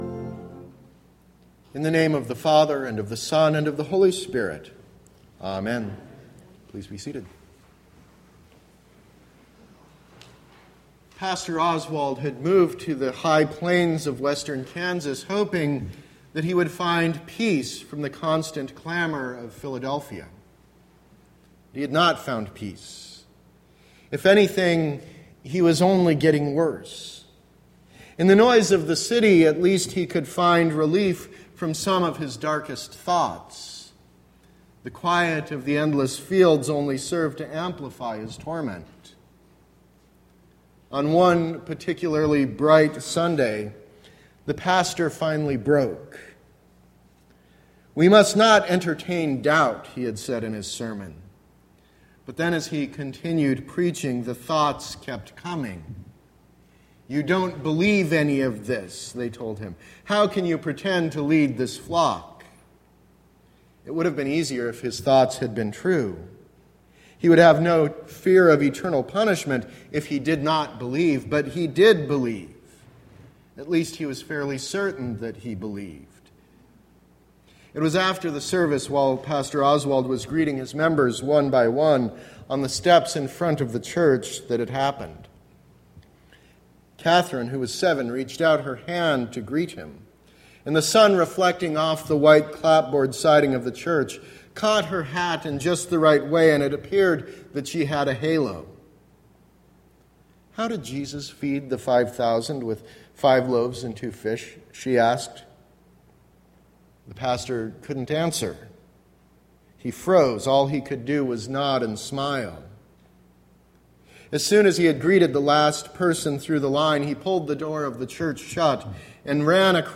Sermon – October 23, 2016 – Advent Episcopal Church